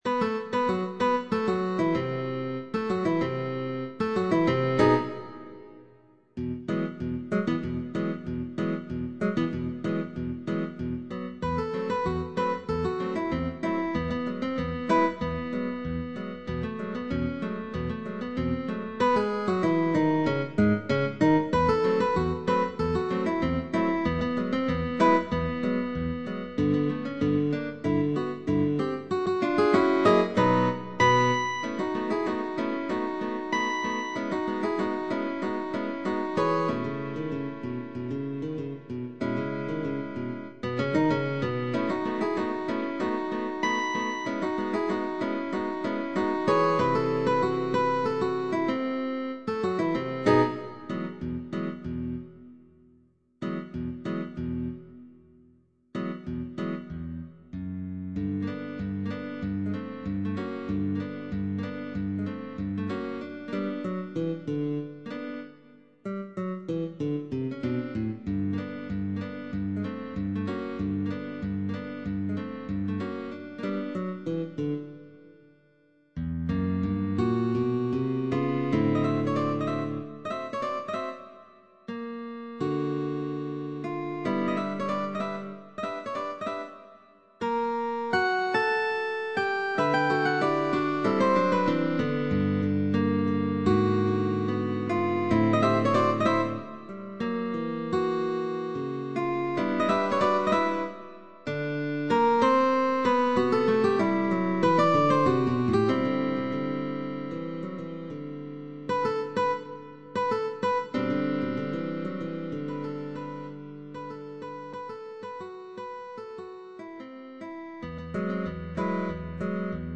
GUITAR DUO
Each quotation is followed by banjo imitations.
Harmonics, slurs, high positions, chords,…